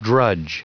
Prononciation du mot drudge en anglais (fichier audio)